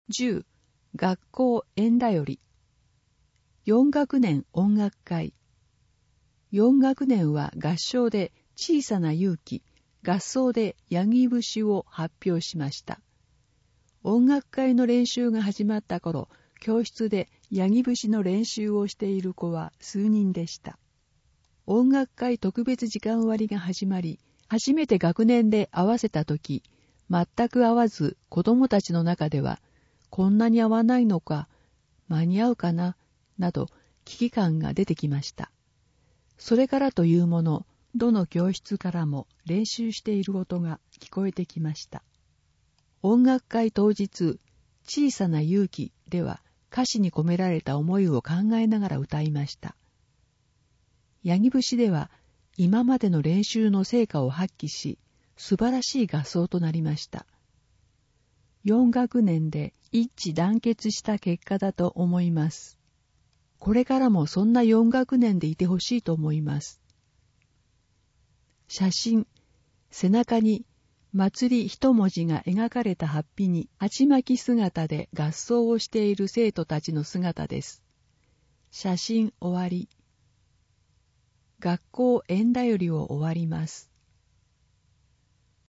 毎月発行している小布施町の広報紙「町報おぶせ」の記事を、音声でお伝えする（音訳）サービスを行っています。 音訳は、ボランティアグループ そよ風の会の皆さんです。